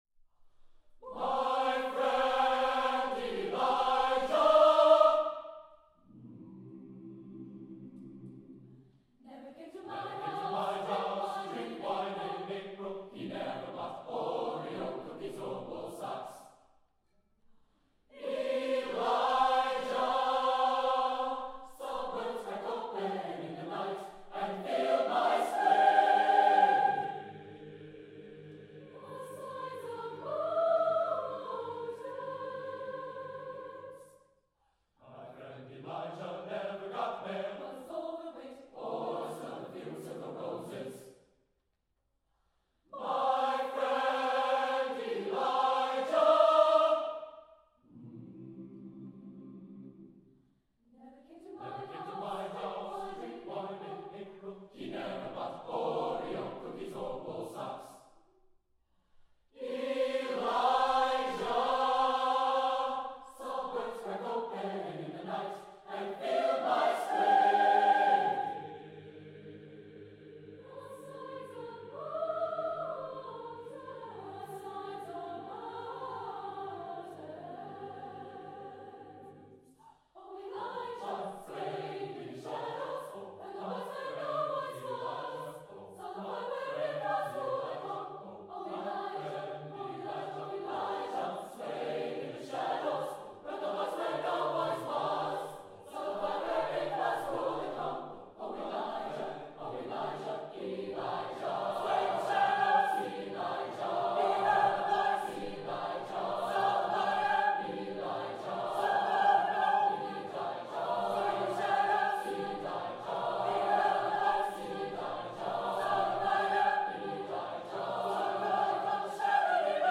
Accompaniment:      A Cappella
Music Category:      Choral
An impressive concert work for ambitious choirs.